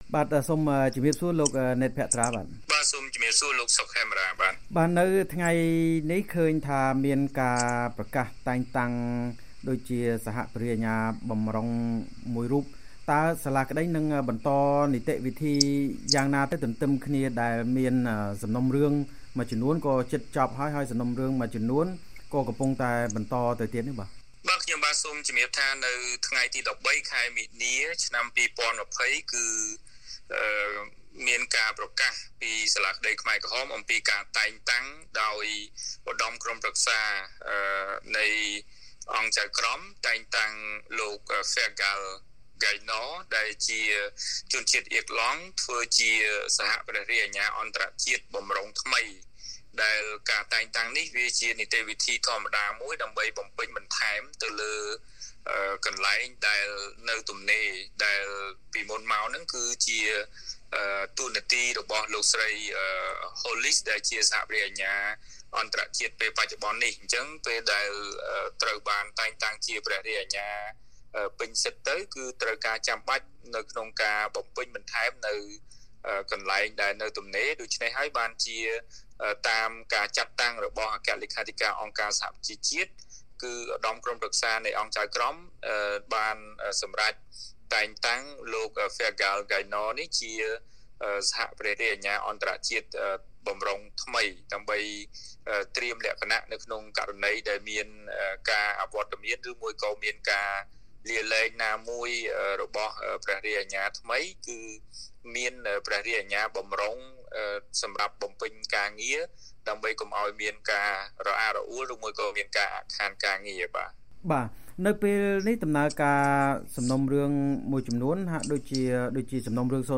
បទសម្ភាសន៍ VOA៖ សាលាក្តីខ្មែរក្រហមបន្ថែមសហព្រះរាជអាជ្ញាបម្រុងអន្តរជាតិថ្មីមុនបញ្ចប់ប្រតិបត្តិការ